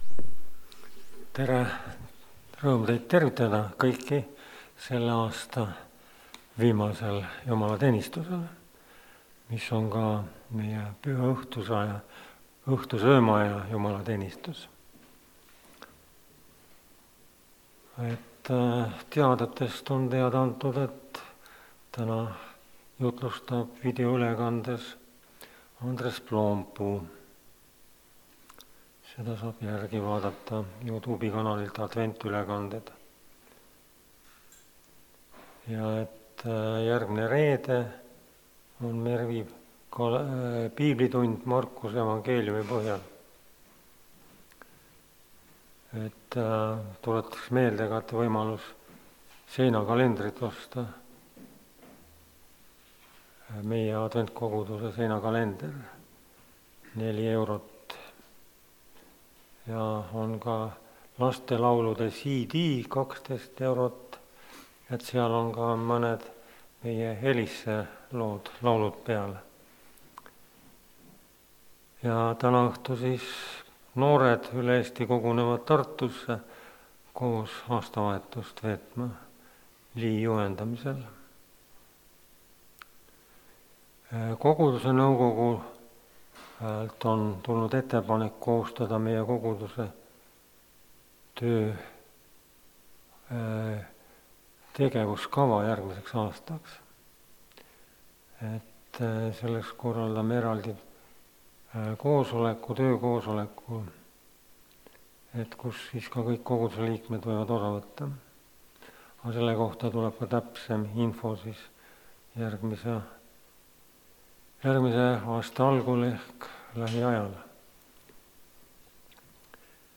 Aasta viimane osasaamisteenistus (Rakveres)
Koosolekute helisalvestused